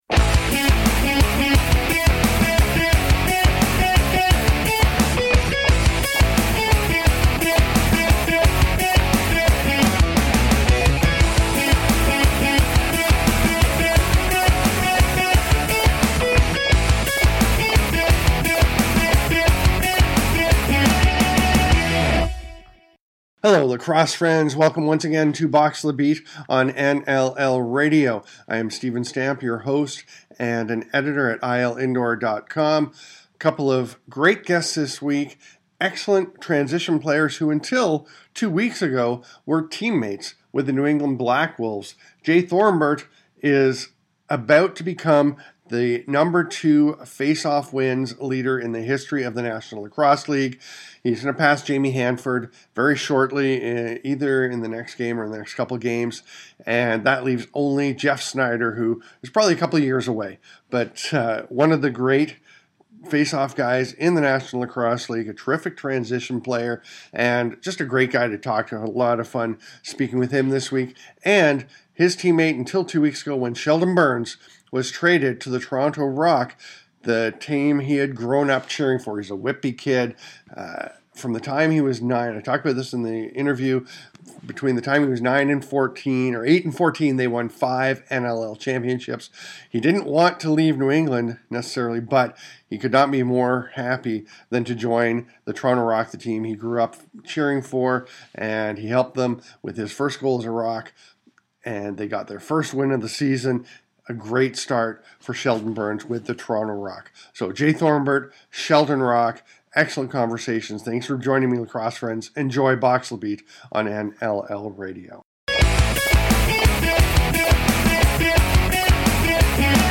talking to a pair of former teammates